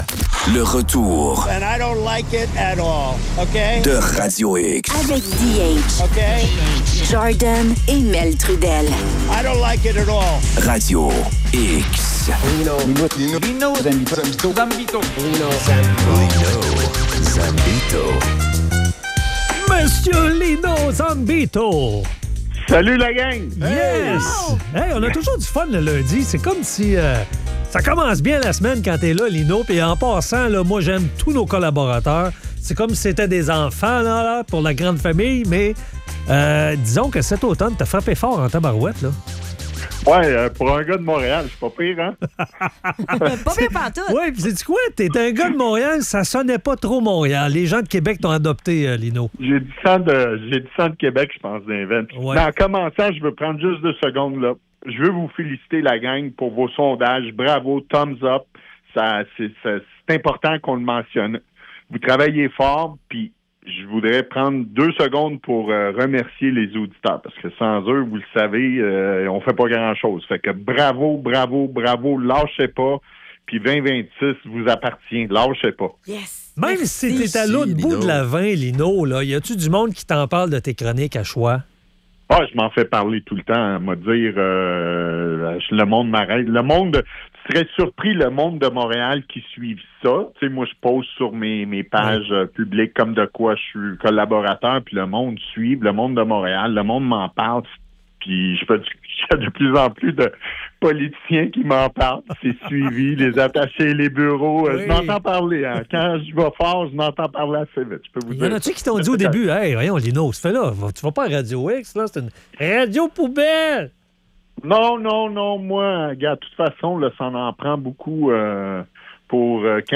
La chronique de Lino Zambito.